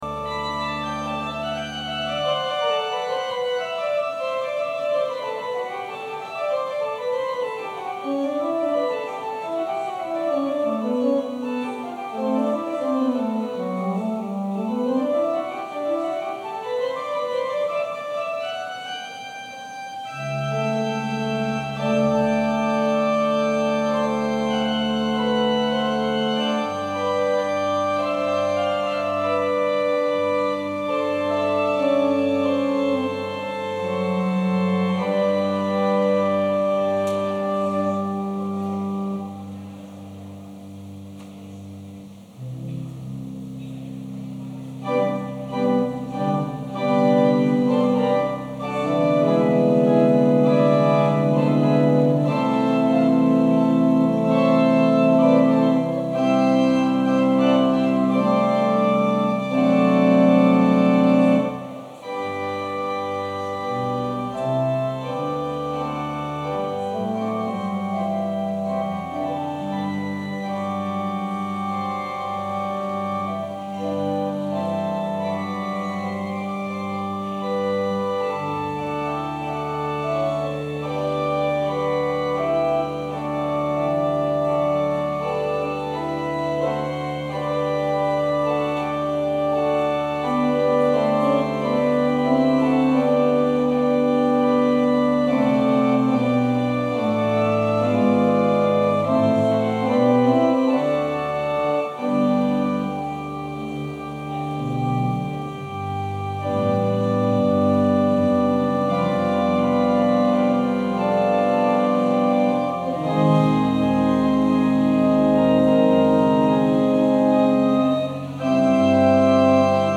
Passage: John 18: 33-37 Scriptures and sermon from St. John’s Presbyterian Church on Sunday